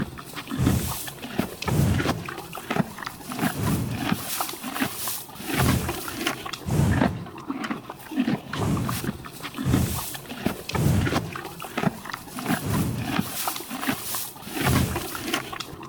horses / eat.ogg
eat.ogg